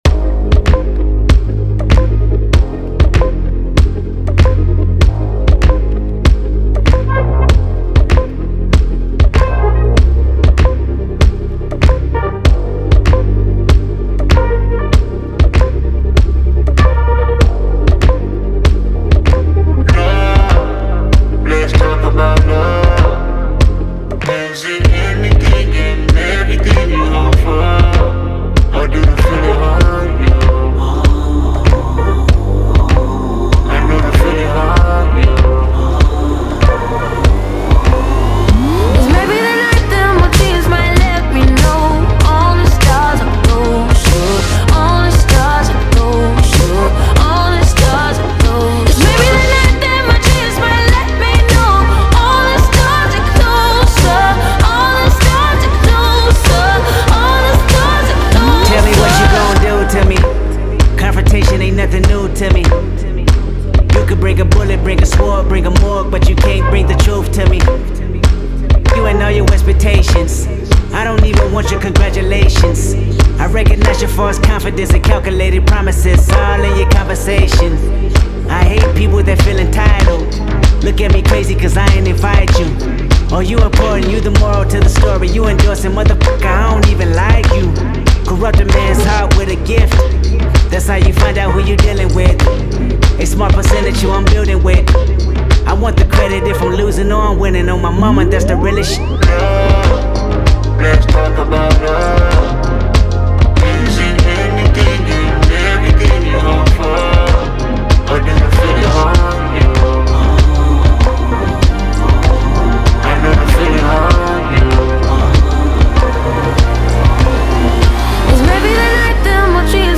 آهنگ پاپ خارجی